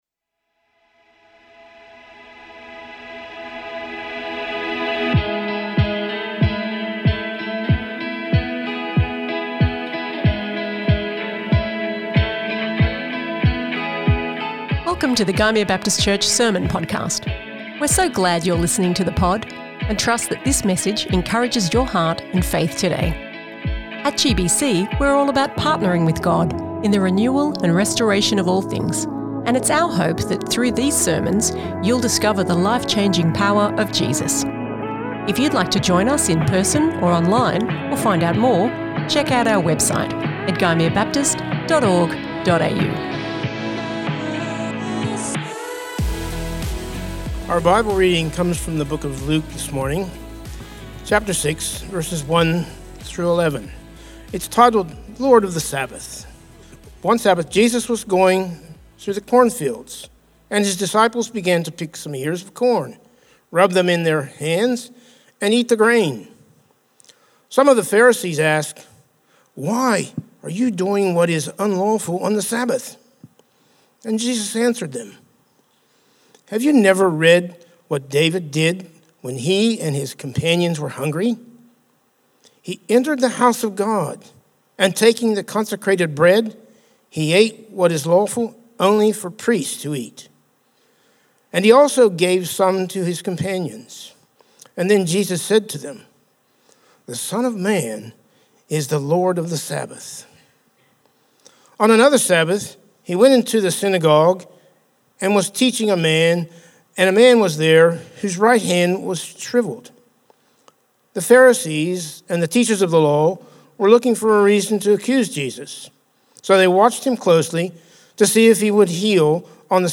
GBC | Sermons | Gymea Baptist Church